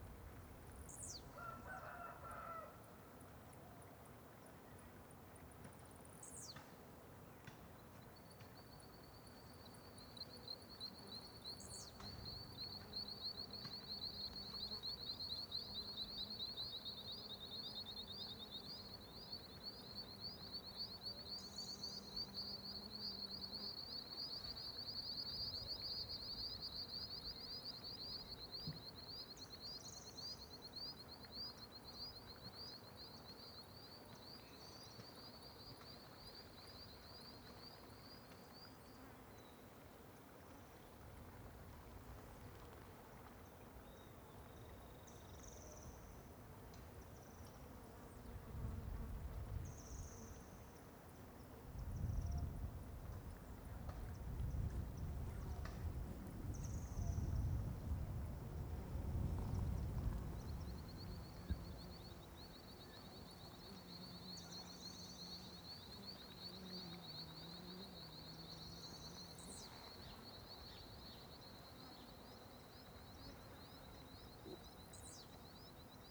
CSC-03-144-OL- Ambiente em descampado perto de cidade.wav